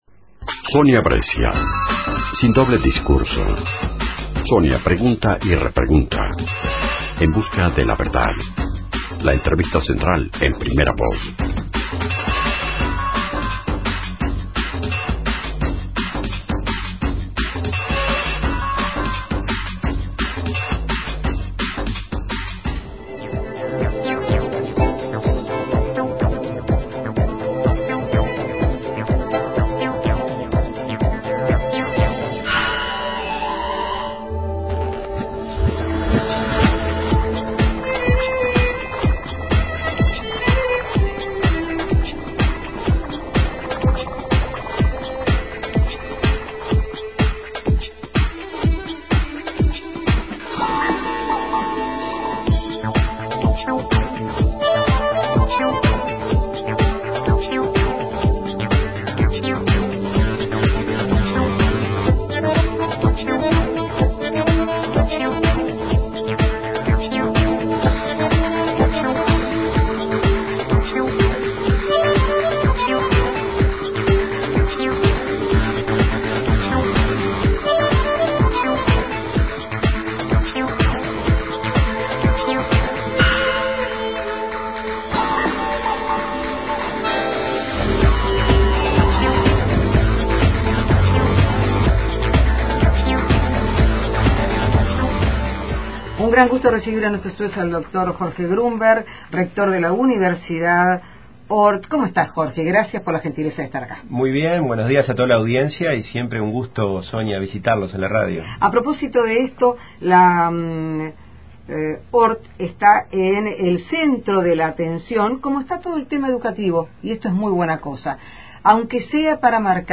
Entrevista en 1410 AM Libre